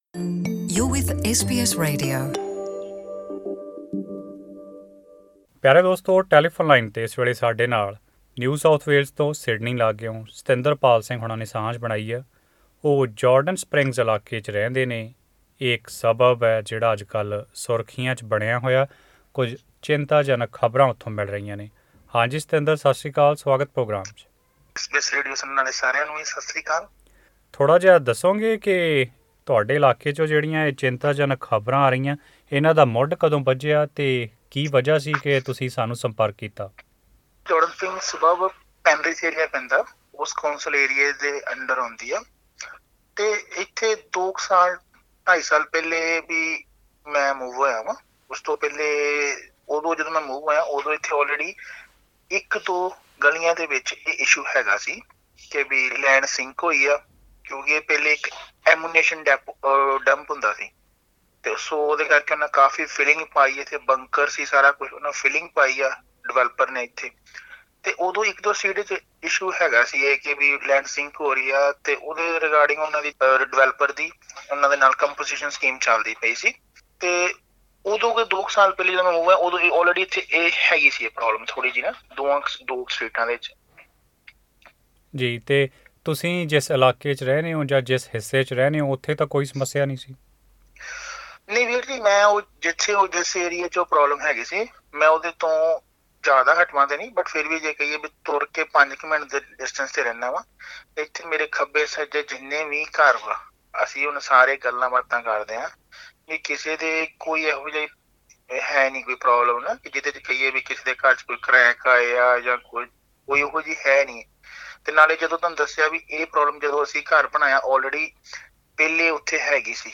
Jordan Springs resident share concerns after media labels their locality a ‘sinking suburb’